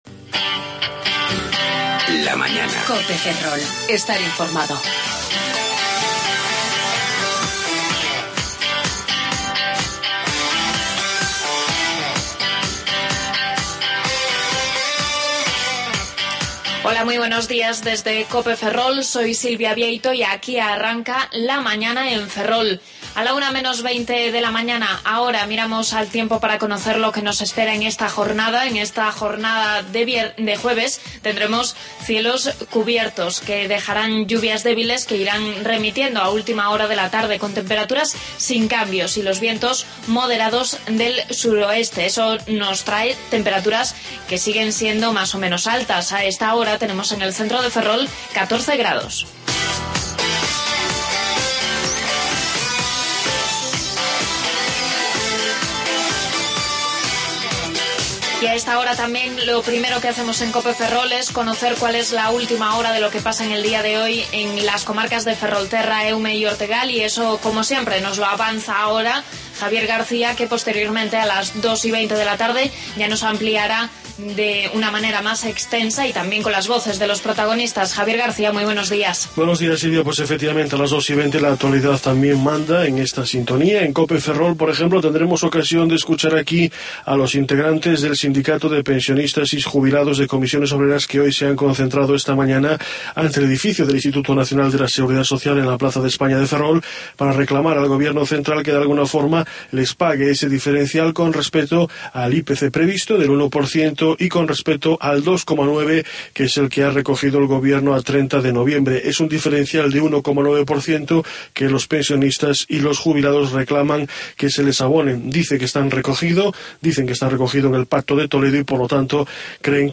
AUDIO: Avances informativos y contenidos de Ferrol, Eume y Ortegal.